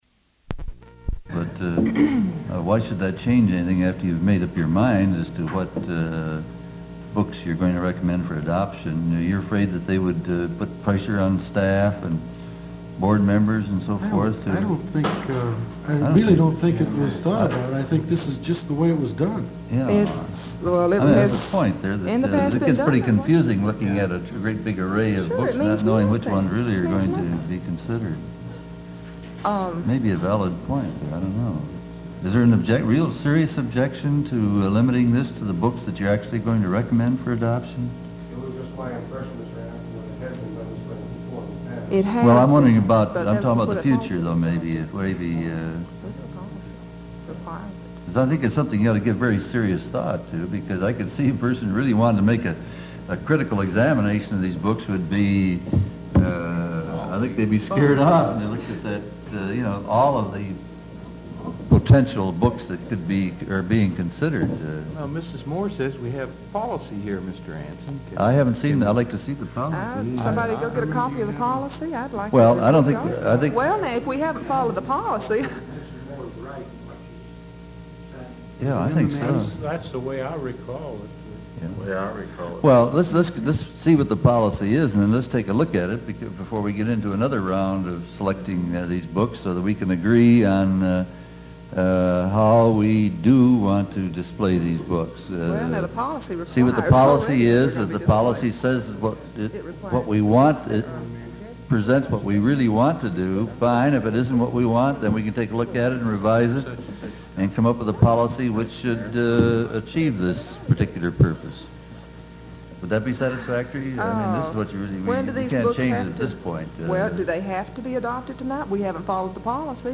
(Listen to this clip from a 1974 school board meeting in which Moore makes her case: Kanawha Board 4 – 11-74 – 3 .